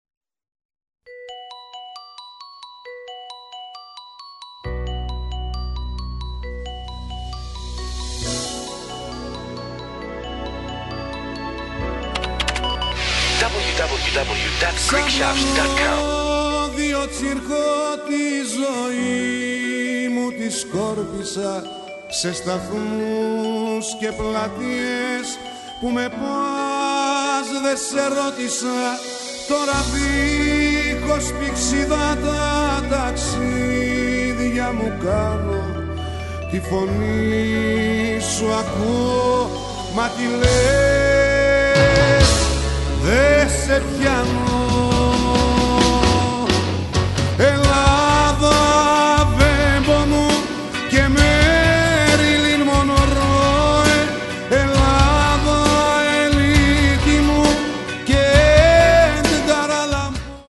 CD2 - STUDIO